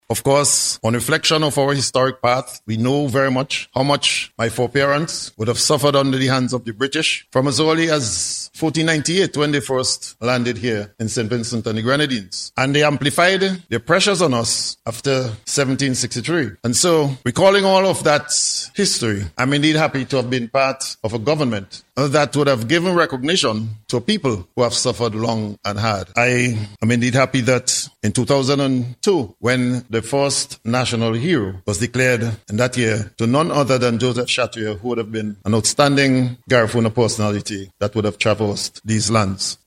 He made this statement during yesterday’s ceremony for the Grand Opening of the Lounge at the Argyle International Airport.